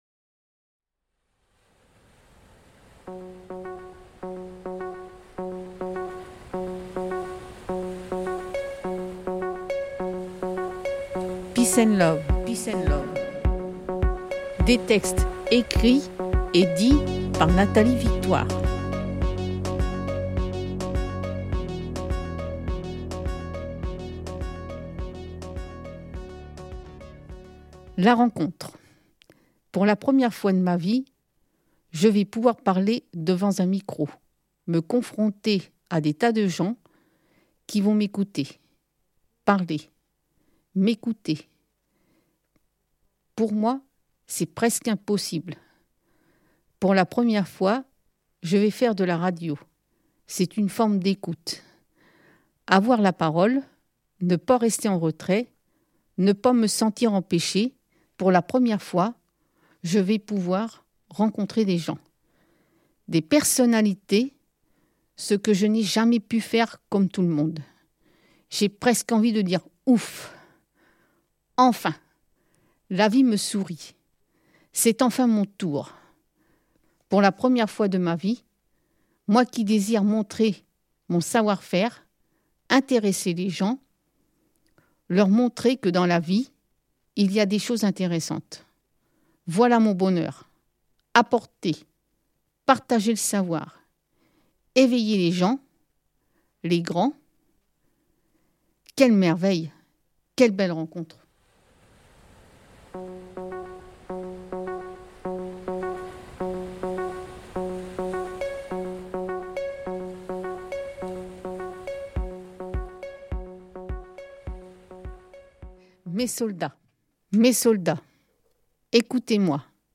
Ses productions ont été diffusé sur notre antenne. lors de notre semaine spéciale SECD.